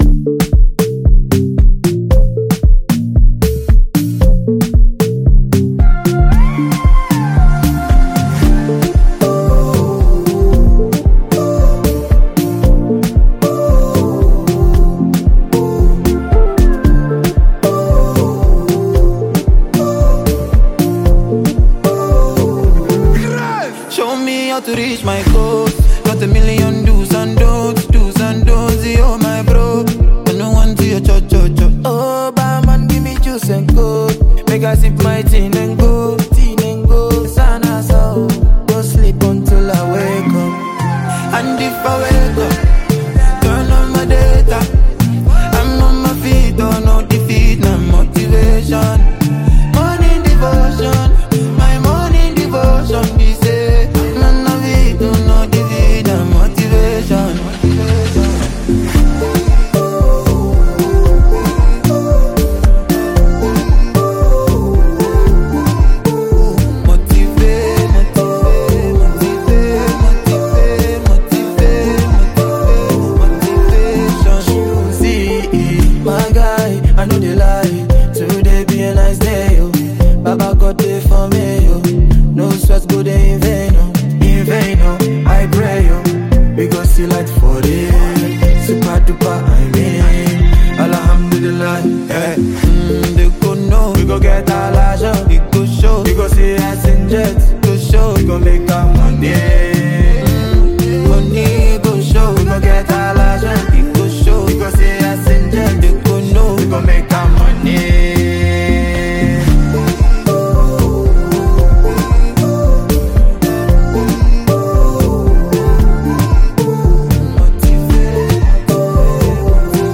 Genre: Afrobeat / Inspirational